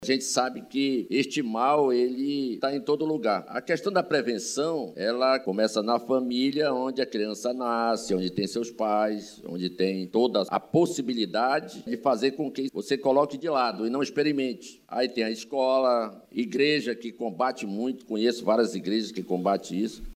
A prevenção e o combate às drogas nas escolas municipais de Manaus foram colocados em debate, durante o Grande Expediente da Câmara Municipal de Manaus – CMM, desta terça-feira 24/02.
Um deles, o vereador Professor Samuel, do PSD, destacou o papel das instituições no combate às drogas.